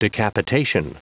Prononciation du mot decapitation en anglais (fichier audio)
Prononciation du mot : decapitation